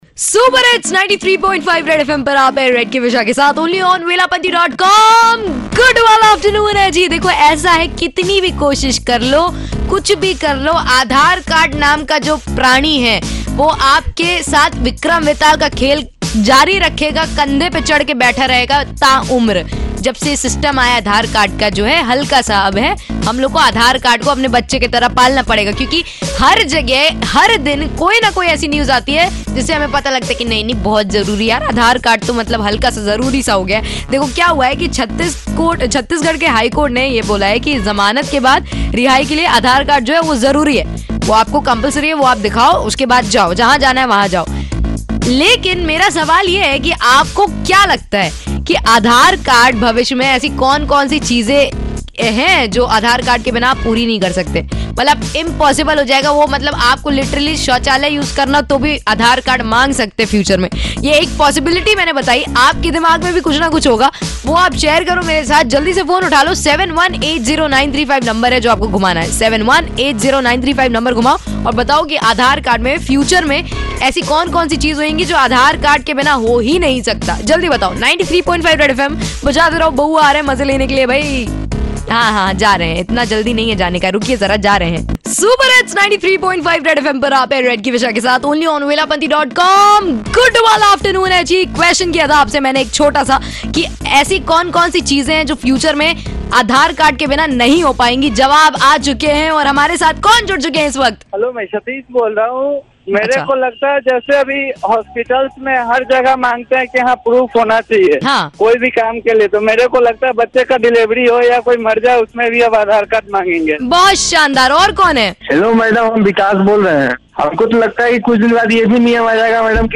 Vox POP